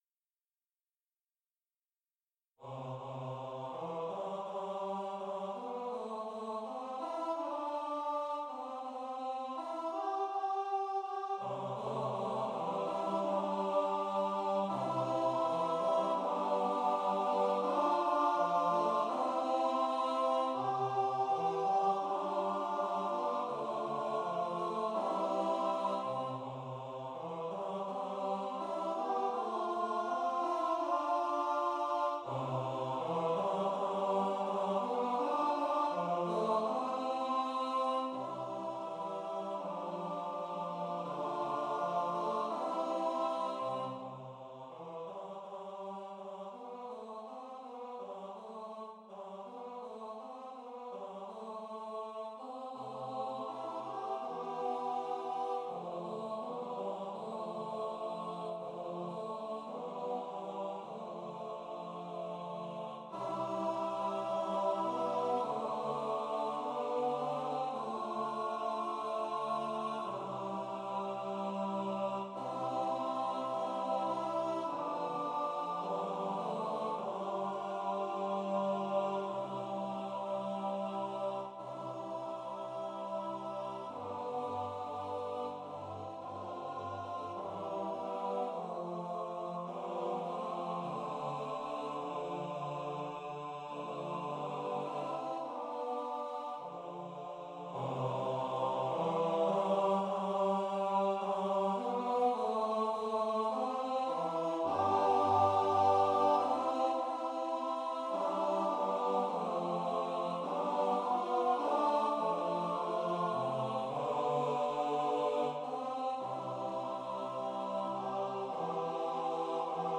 An a cappella piece for male voice choir (TTBB)